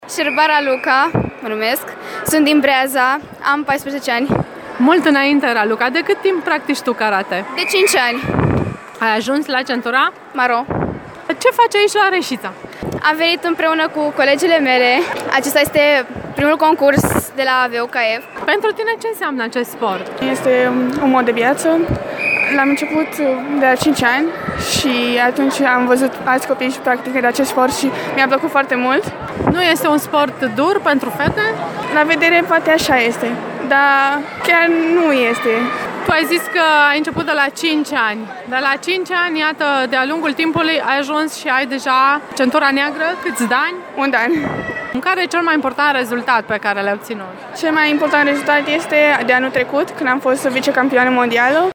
Am dialogat cu câteva sportive de top ale României: